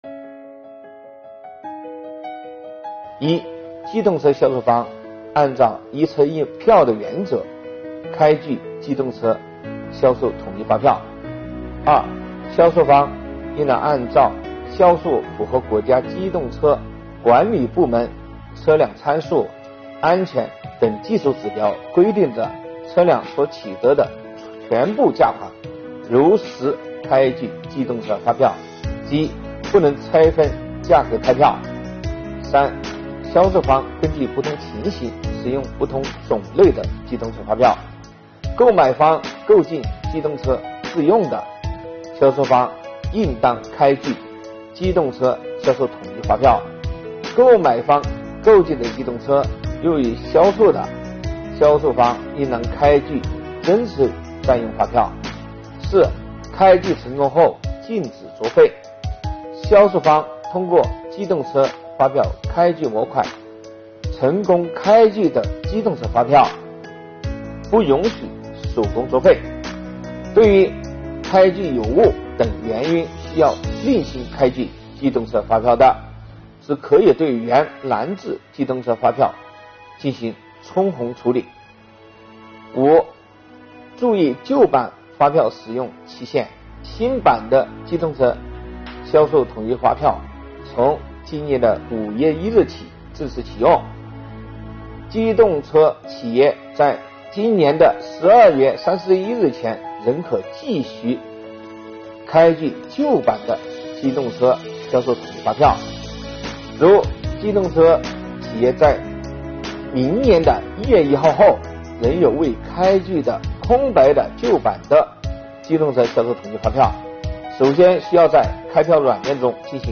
近日，国家税务总局推出“税务讲堂”课程，国家税务总局货物和劳务税司副司长张卫详细解读《办法》相关政策规定。